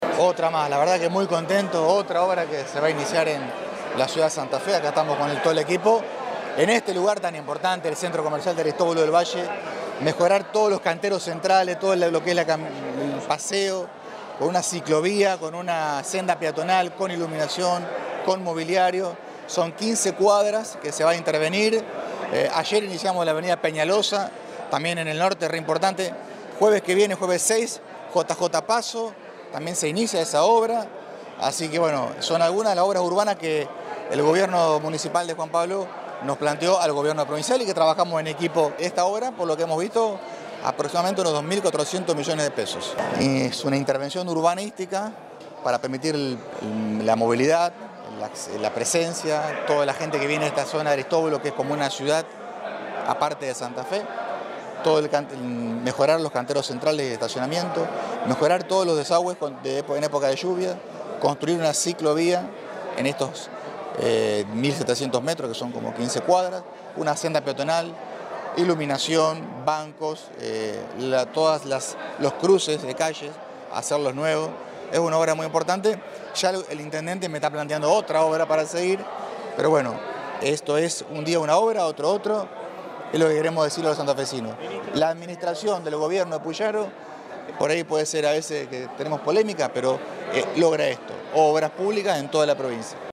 Declaraciones de Enrico